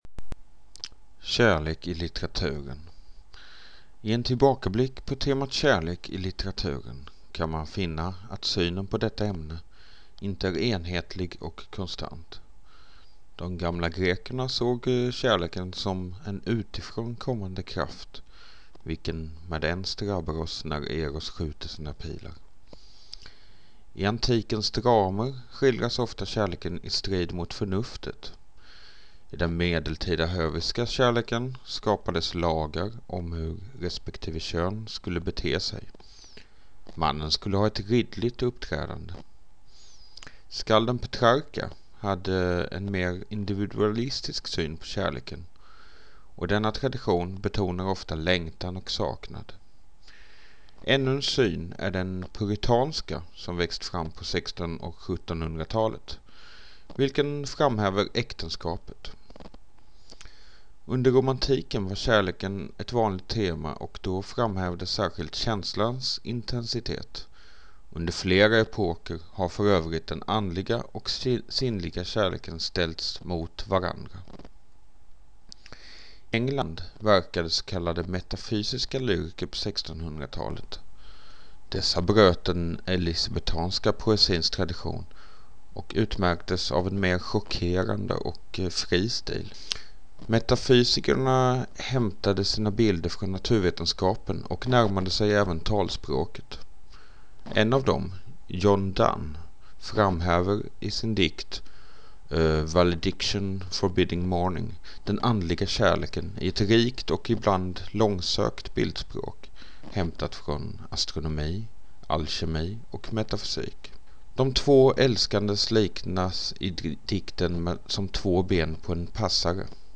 upplasningmp3.mp3